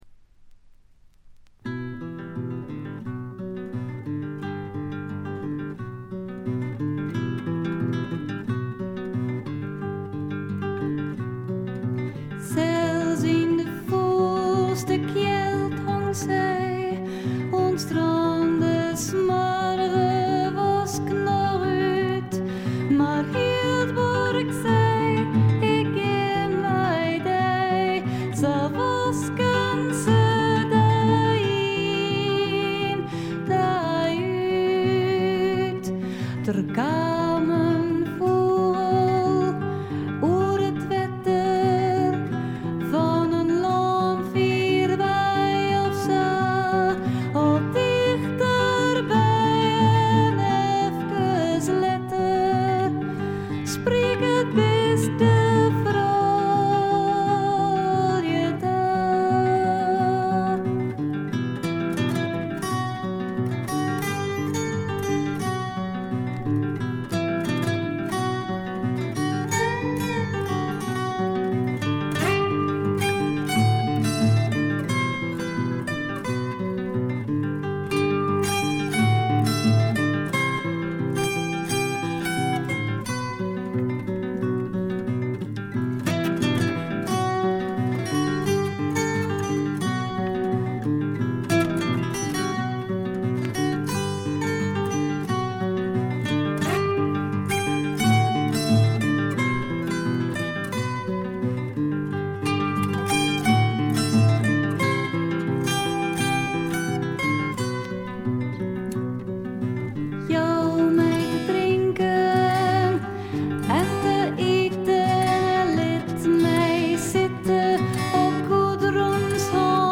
異国情緒が漂う女性ヴォーカルの美声に思わずくらくらしてしまうフィメールフォークの名作でもあります。
アコースティック楽器主体ながら多くの曲でドラムスも入り素晴らしいプログレッシヴ・フォークを展開しています。
試聴曲は現品からの取り込み音源です。